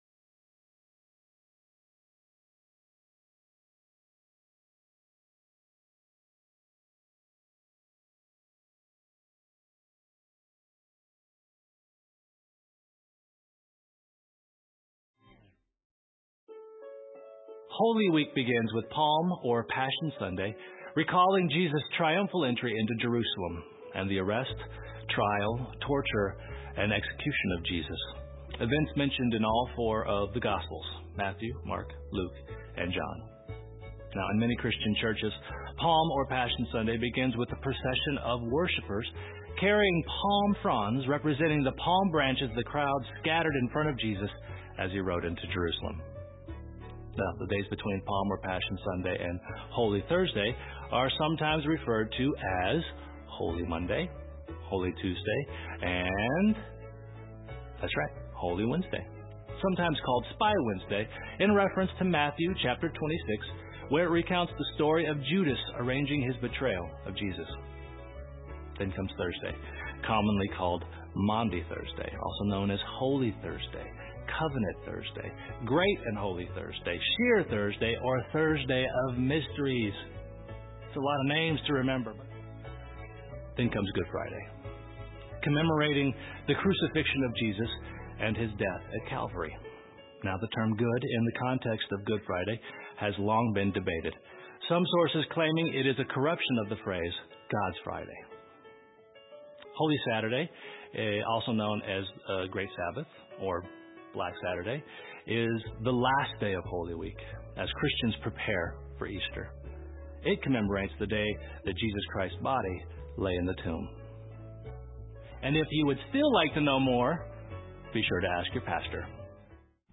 Sermon:Palm Sunday - Why “Hosanna!” Became “Crucify Him!”?
2021 We are RECEIVED as the Beloved of God The actual worship service begins 15 minutes into the recordings.